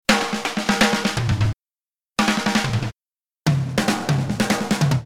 Что за барабаны звучат?